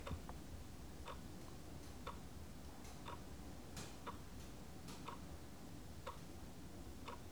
Les sons ont été découpés en morceaux exploitables. 2017-04-10 17:58:57 +02:00 2.7 MiB Raw History
bruit-horloge_01.wav